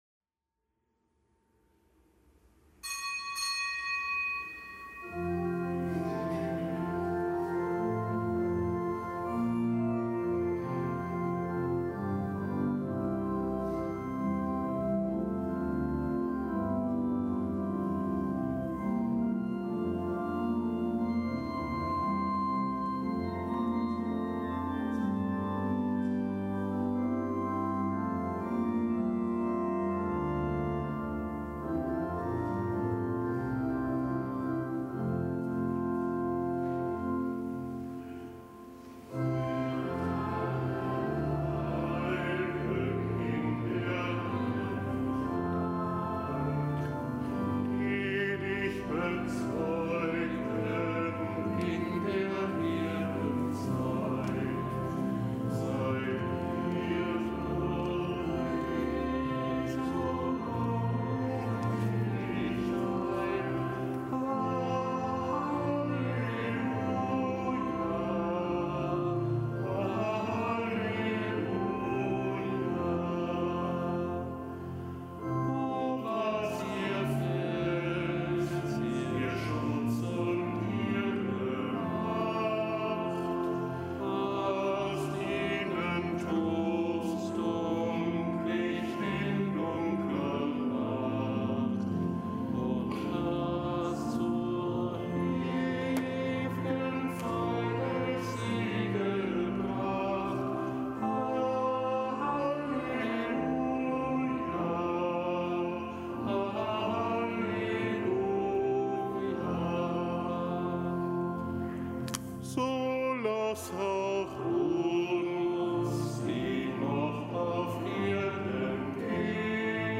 Kapitelsmesse am Gedenktag des Heiligen Paul Miki und Gefährten
Kapitelsmesse auf dem Kölner Dom am Gedenktag des Heiligen Paul Miki und Gefährten.